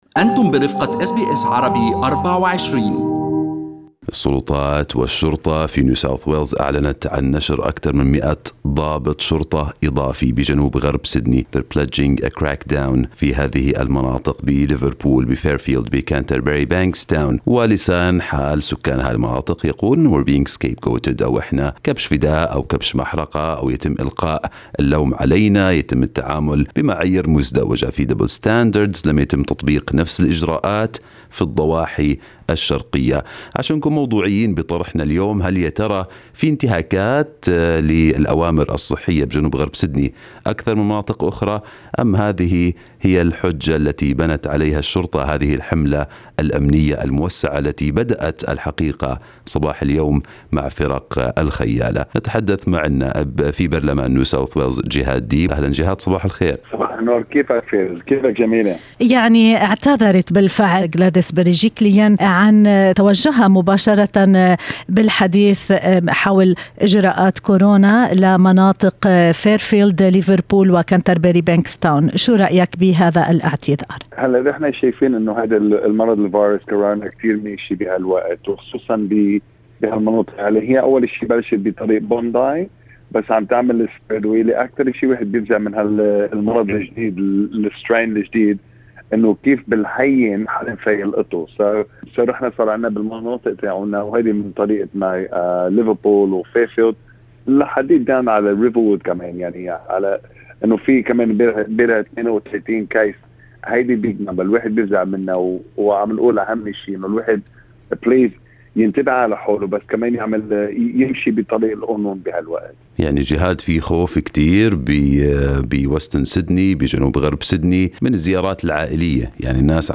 وقال النائب في برلمان نيو ساوث ويلز جهاد ديب في حديث لأس بي أس عربي24 إن فيروس كورونا يتفشى بسرعة في غرب المدينة ومعظم الإصابات هي بالمتحور الجديد "دلتا".